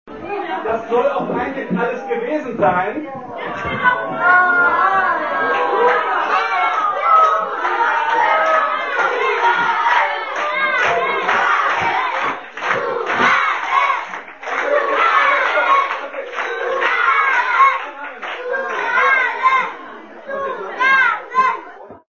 live 1